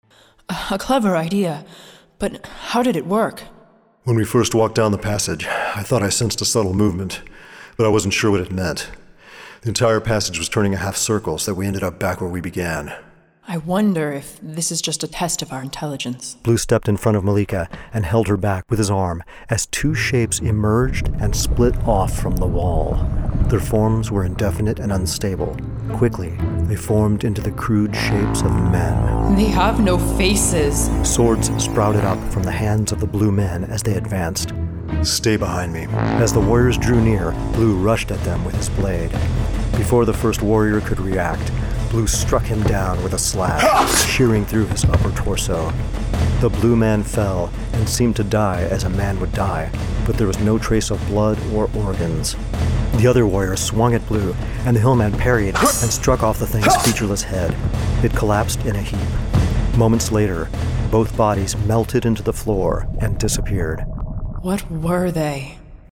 Dream Tower Media creates full audiobook dramas featuring professional voice actors, sound effects, and full symphonic musical scores.
Excerpts from The Blue Lamp audiobook drama